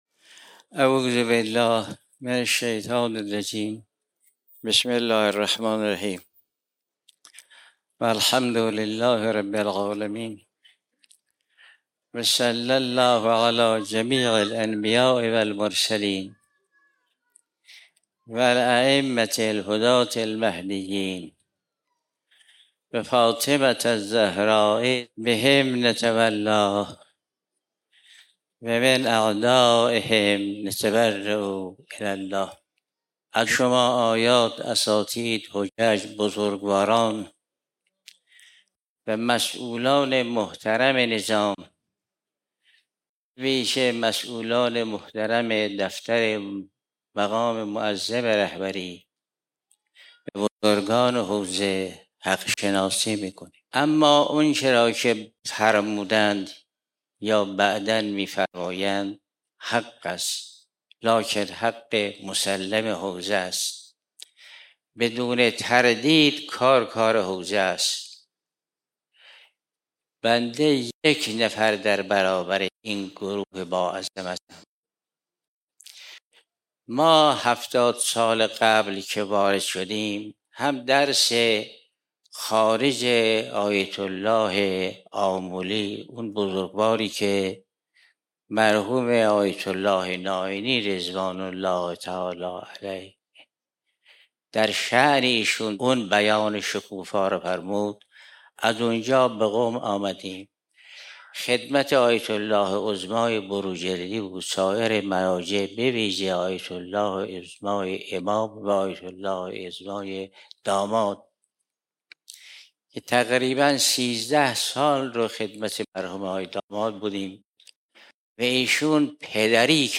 سخنرانی آیت الله العظمی جوادی آملی در همایش بین المللی تفسیر تسنیم